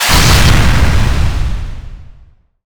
explosion_large.wav